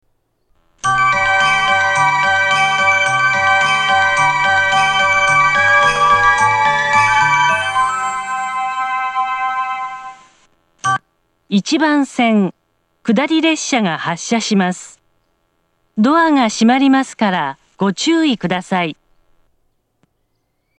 列車の本数が少ないので駅員放送が入ることが多く、メロディー・放送に被ることも多いです。
１番線下り発車メロディー